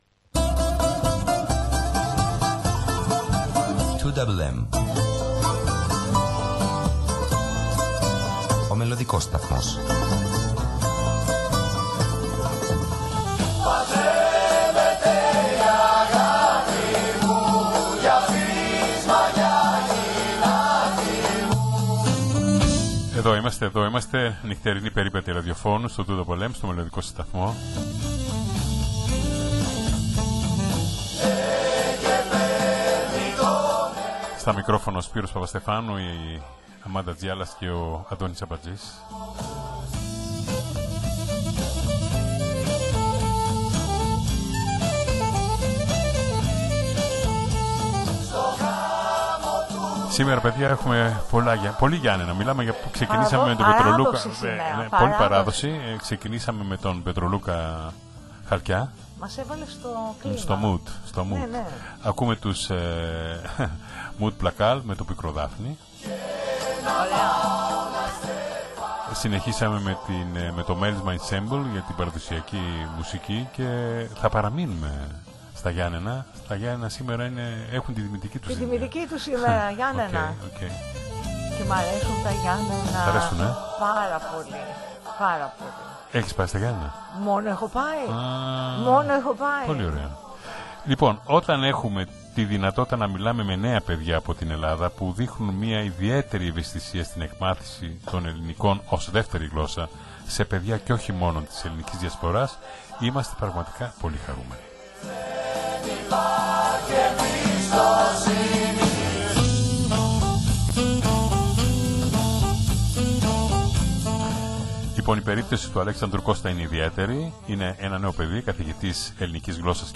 σε ζωντανή σύνδεση